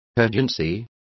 Also find out how prisa is pronounced correctly.